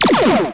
TORPEDO.mp3